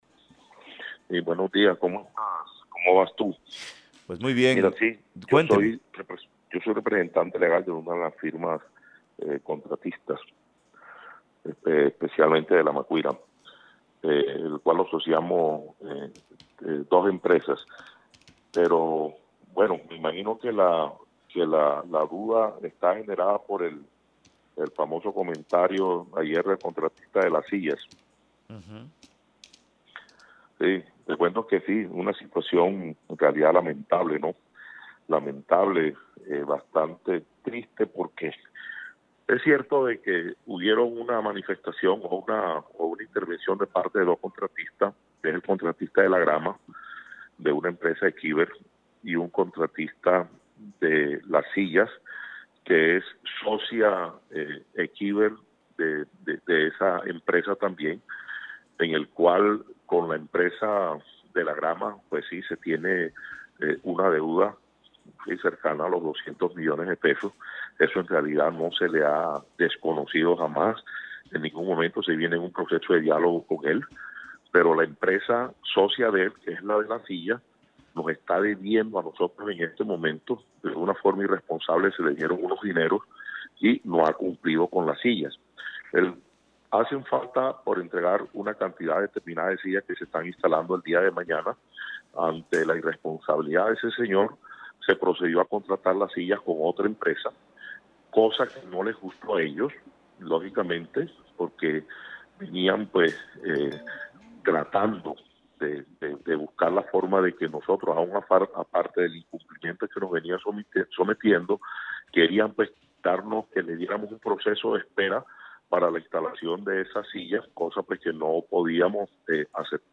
Entrevista Radio Galeón de Caracol: